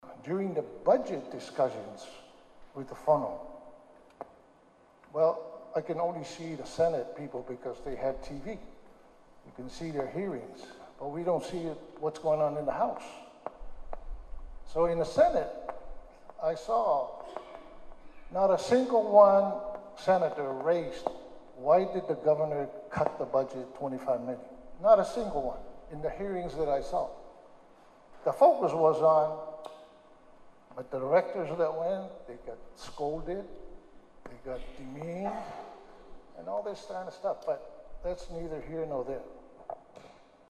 His first public comments regarding some of the issues raised were made at the Medicaid Summit earlier this week.